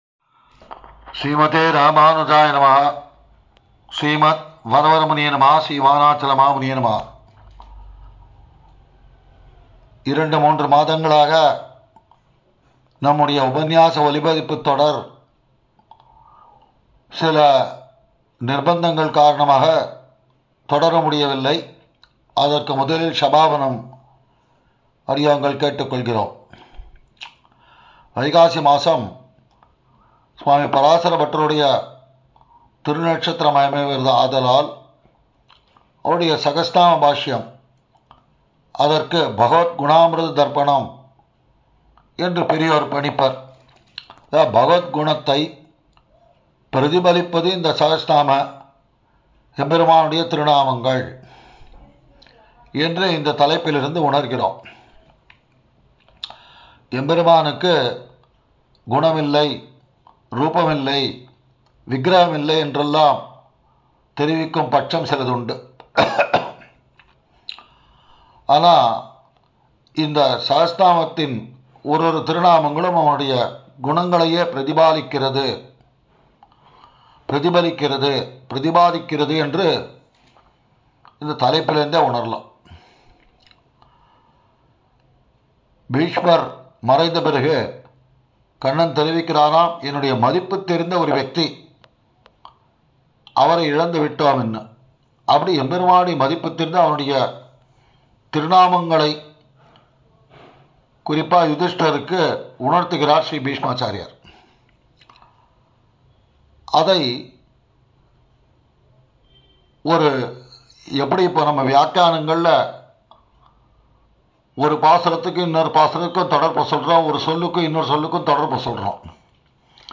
ஸ்ரீவிஷ்ணு ஸஹஸ்ரநாம பகவத் குண தர்பணத்தில் இருந்து அளிக்கவிருக்கும் உபந்யாசங்கள் பற்றிய முன்னுரை,